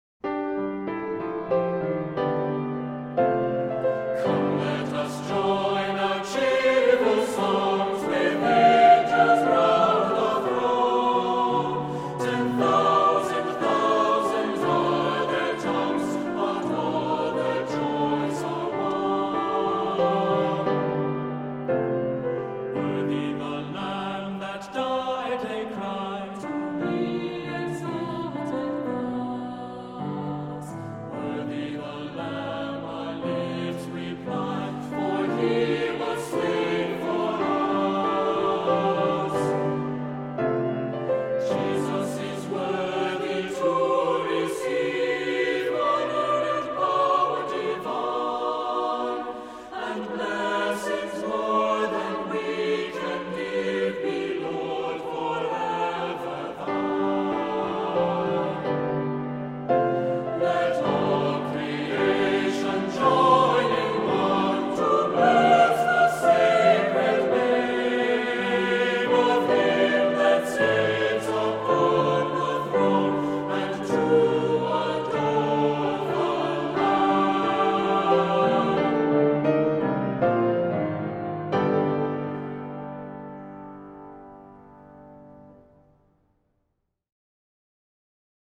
Accompaniment:      Keyboard
Music Category:      Christian
This is a jubilant dancelike setting